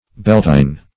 Beltein \Bel"tein\